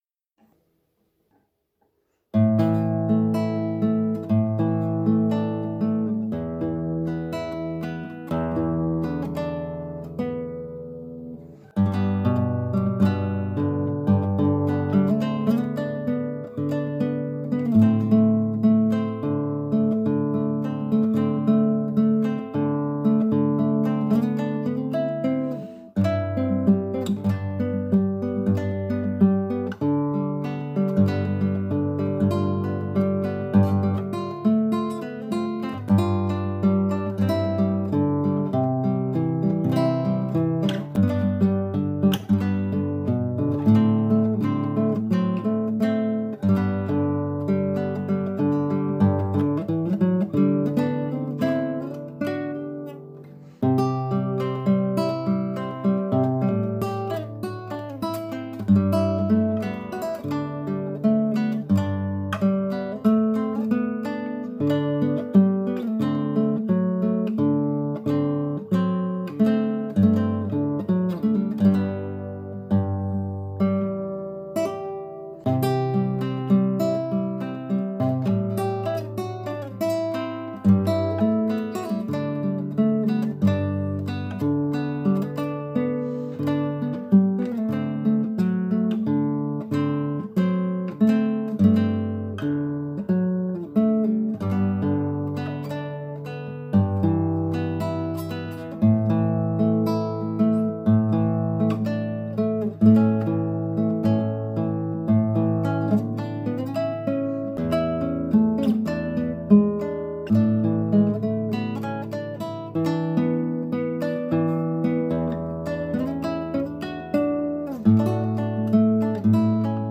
classical guitar arrangement
Voicing/Instrumentation: Guitar